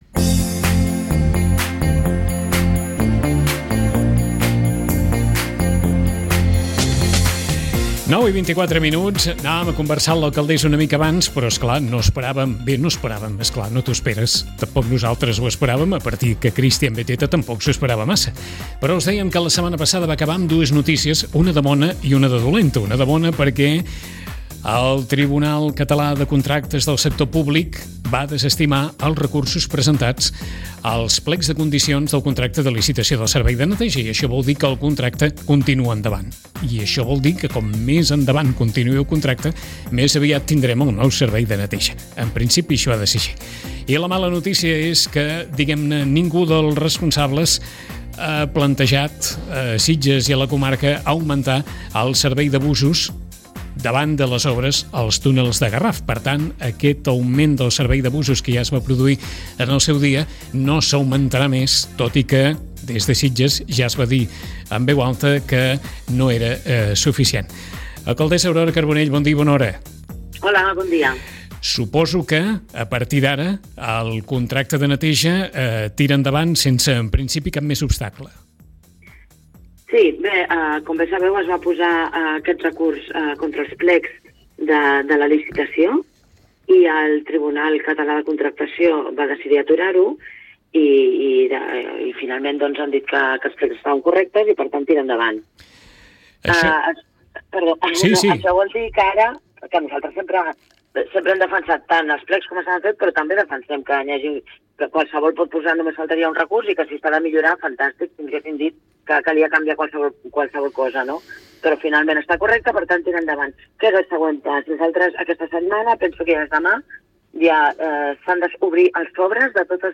imatge: 3cat Si el desenvolupament del procés no viu nous entrebancs, el mes de setembre podria activar-se el nou contracte de neteja, després que el tribunal de contractació de Catalunya hagi desestimat els recursos presentats als plecs de clausules de la licitació del nou contracte. Així ho ha confirmat l’alcaldessa Aurora Carbonell, amb la qual també ens hem referit a les obres d’Adif als tunels del Garraf i també al pont dels ocells del carrer Sant Honorat. Les obres als túnels començaran el proper dilluns 16 de març, i deixaran la connexió entre Sitges i Barcelona amb dos trens per hora i sentit.